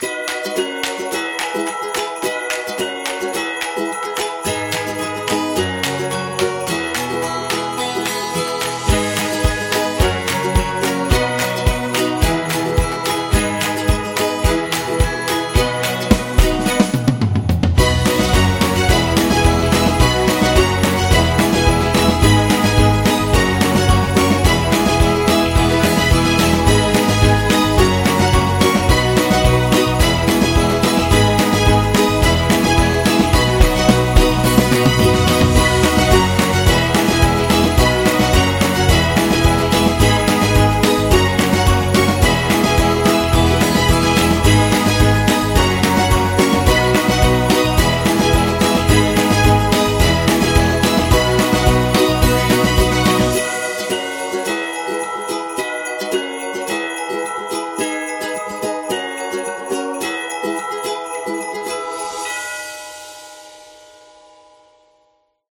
Uplifting, playful, and heartwarming